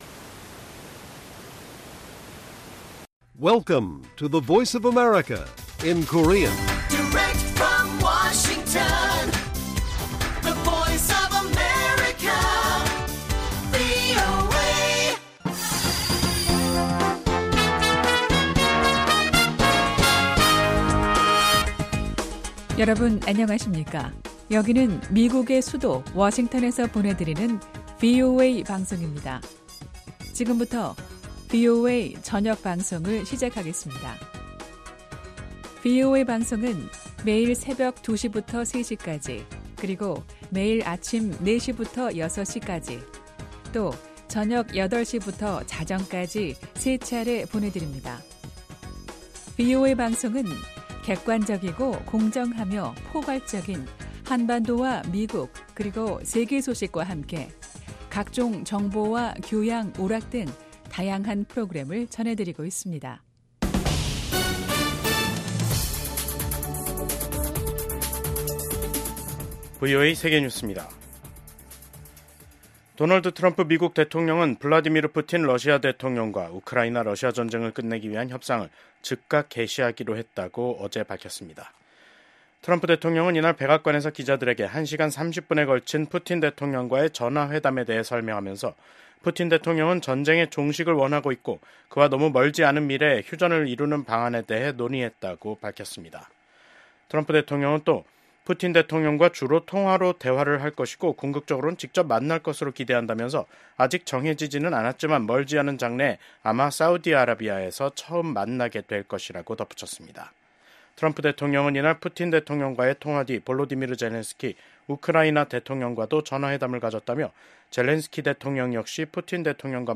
VOA 한국어 간판 뉴스 프로그램 '뉴스 투데이', 2025년 2월 13일 1부 방송입니다. 북한이 남북 화해의 상징인 금강산 관광지구 내 이산가족면회소를 철거 중인 것으로 파악됐습니다. 미국 군함을 동맹국에서 건조하는 것을 허용하는 내용의 법안이 미국 상원에서 발의됐습니다. 미국 경제 전문가들은 트럼프 대통령의 철강∙알루미늄 관세 부과는 시작에 불과하다면서 앞으로 더 많은 관세가 부과돼 미한 경제 관계에 긴장이 흐를 것으로 전망했습니다.